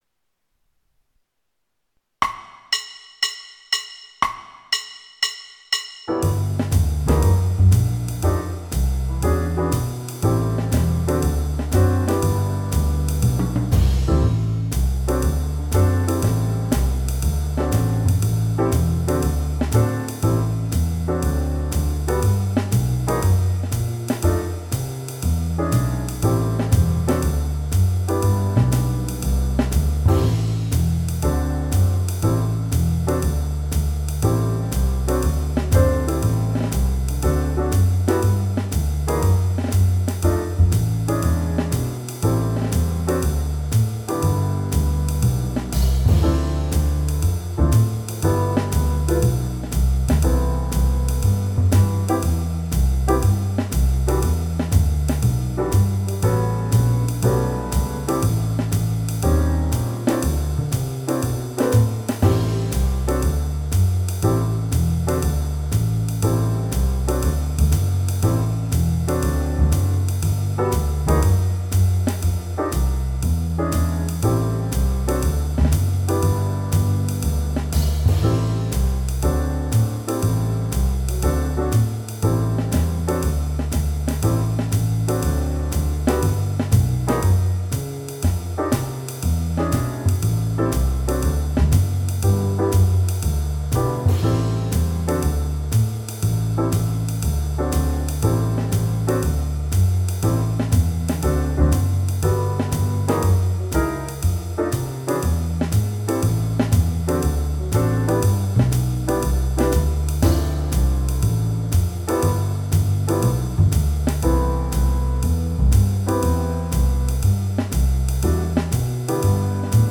BACKING TRACKS: